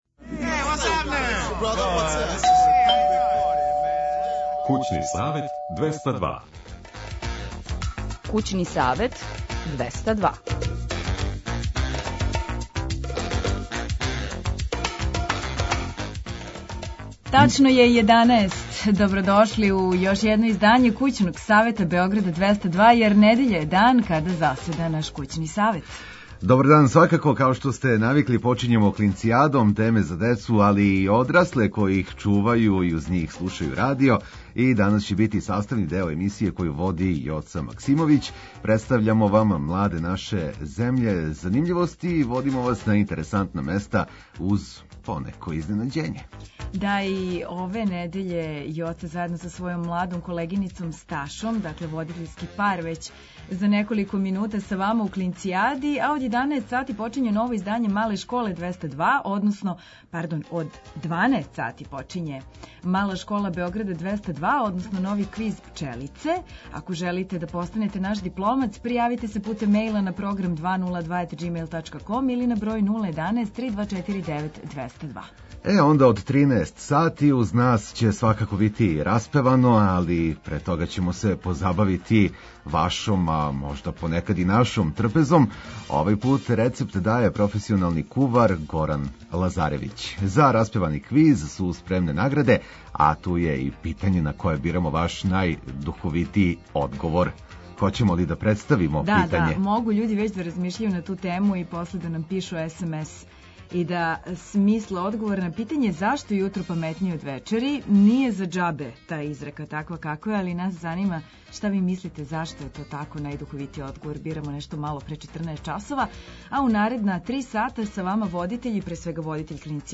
Од подне ће уз нас бити распевано, али пре тога ћемо се позабавити вашом трпезом.